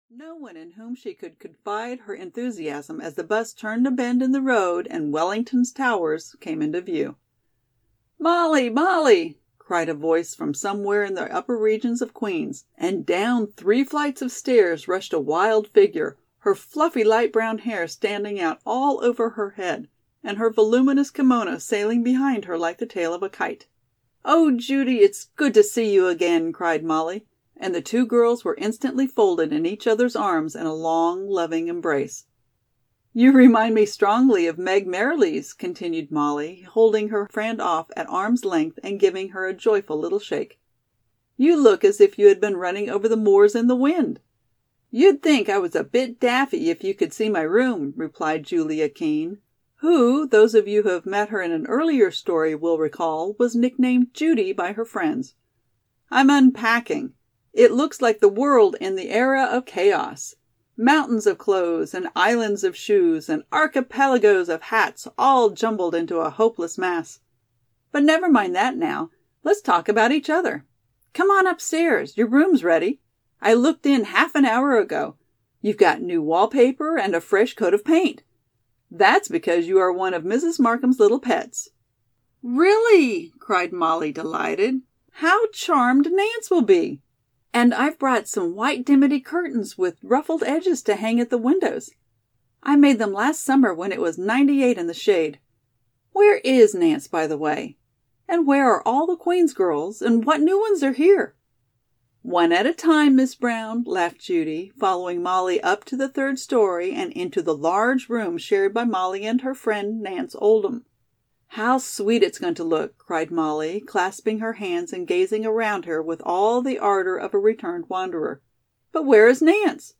Molly Brown's Sophomore Days (EN) audiokniha
Ukázka z knihy